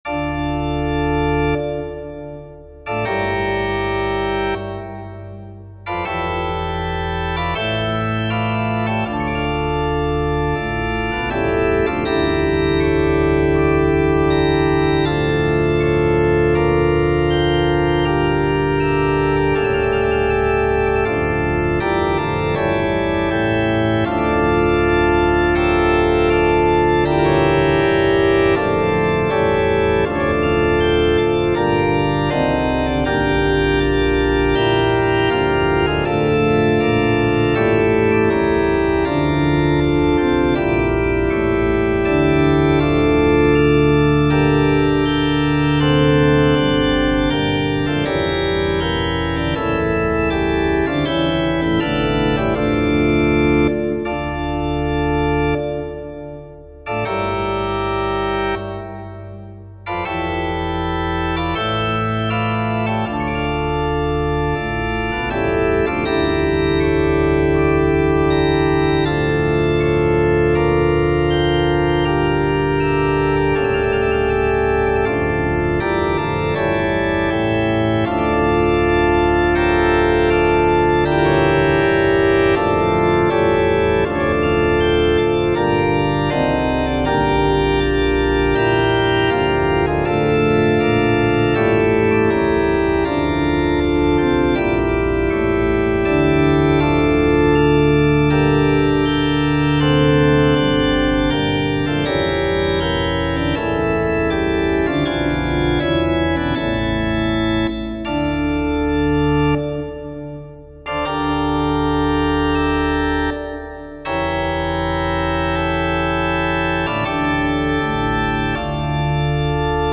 Prelude and Fugue in D minor for Pipe Organ.